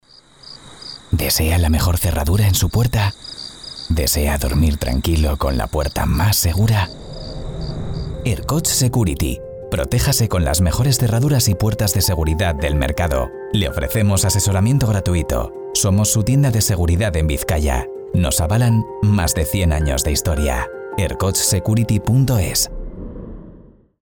Cuña de radio para una empresa de seguridad.